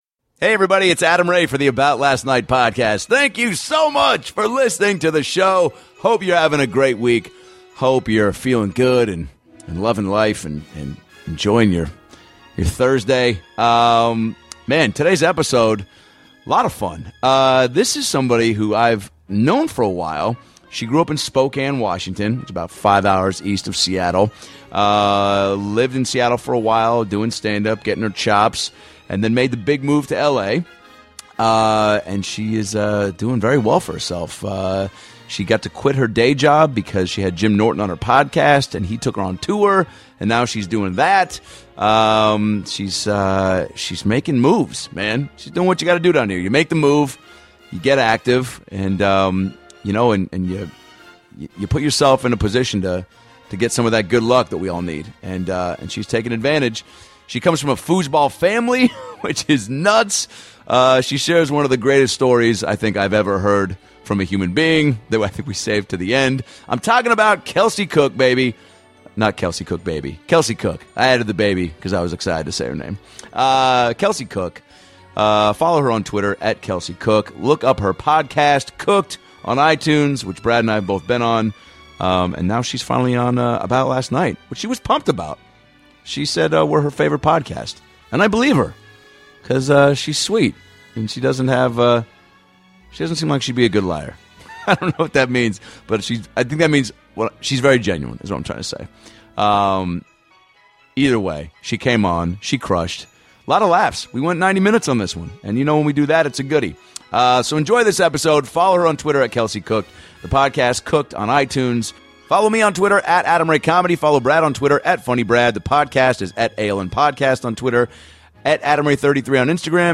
Lotta laughs in this one, and a new career for you to follow.